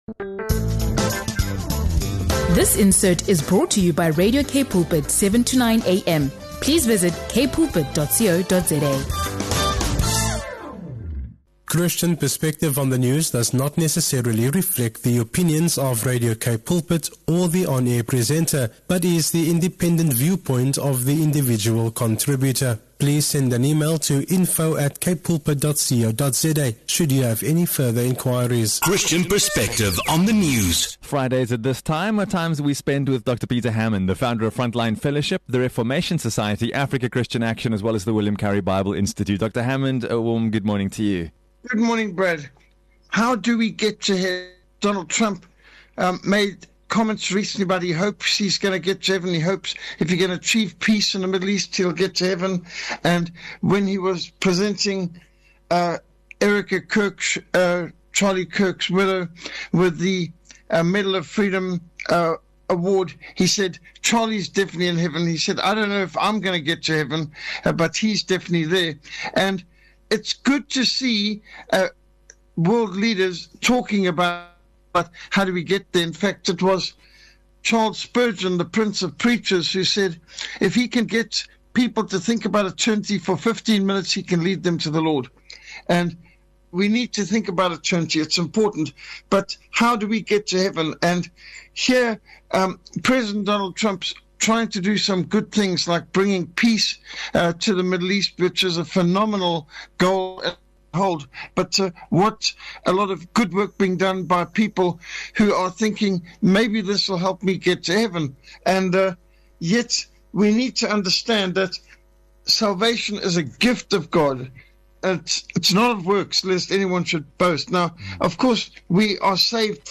The biblical answer is that heaven is a gift from God, received by grace alone through faith alone in Jesus Christ alone. The conversation breaks down why no one is "good enough" by God's perfect standard, explaining concepts like justification and sanctification.